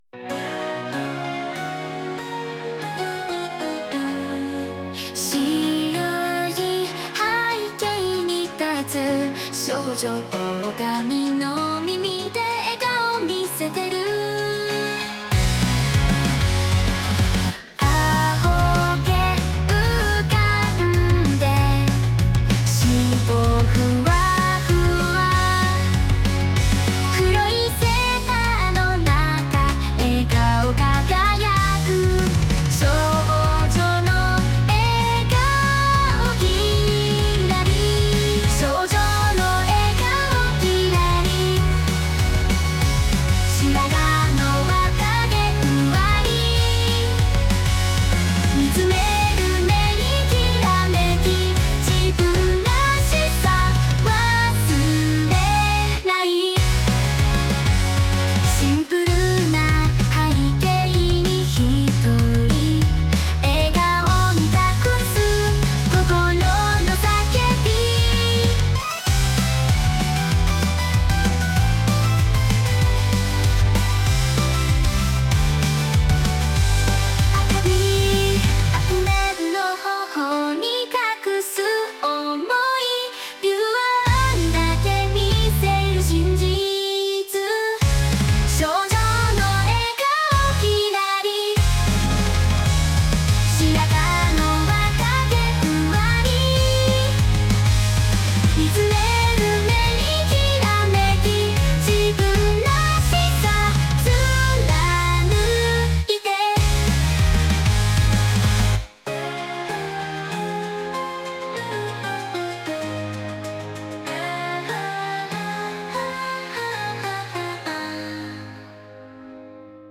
Music EDM Music